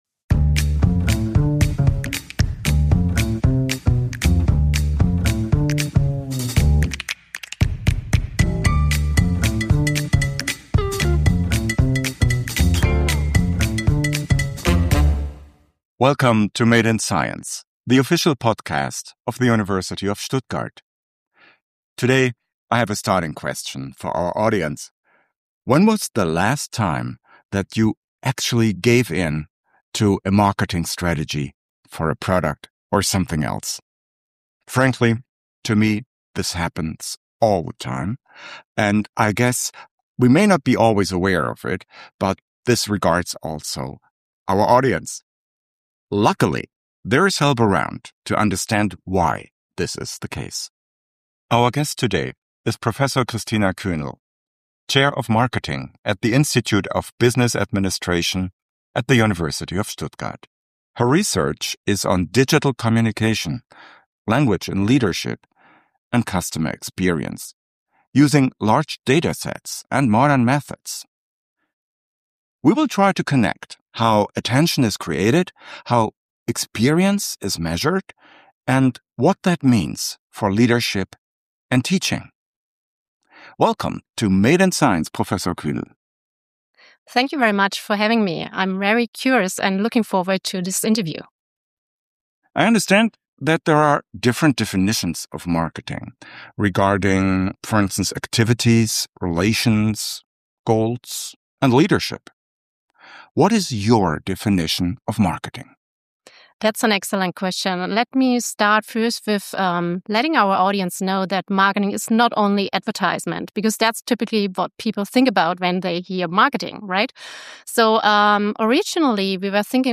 A conversation about visibility, value creation and the future of marketing in the age of AI.